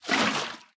Minecraft Version Minecraft Version 1.21.4 Latest Release | Latest Snapshot 1.21.4 / assets / minecraft / sounds / item / bucket / empty3.ogg Compare With Compare With Latest Release | Latest Snapshot